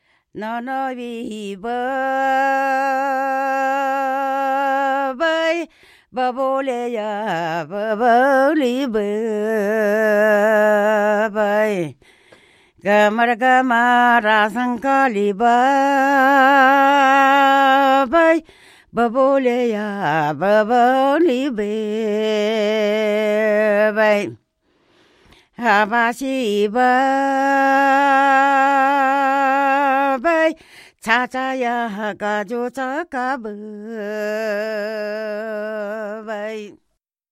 2 May 2018 at 7:50 am About the only significant clue I can pick out is that every line ends with /vai/.
On the other hand, the singing style and the lack of retroflex sounds tell me that the language is not spoken or sung on the Indian subcontinent.